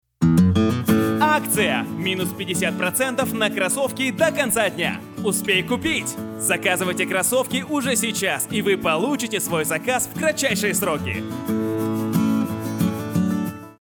Муж, Рекламный ролик
Запись производится в студии, оборудование (Звук.карта, Микрофон, наушники- набор марки «Steinberg»).